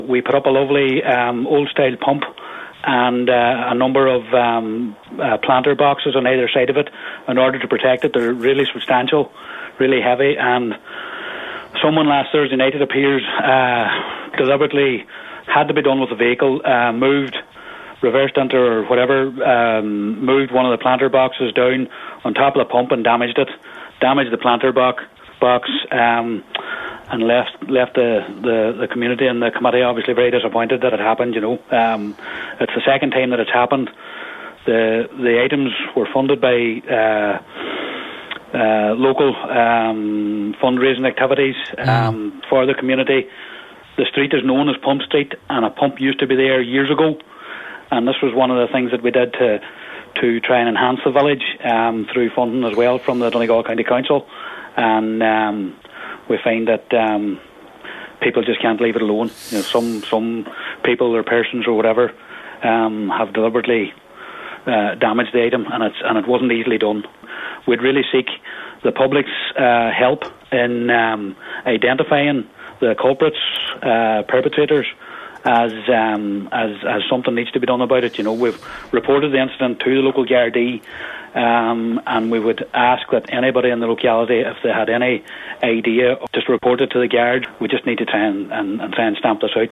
News, Audio, Top Stories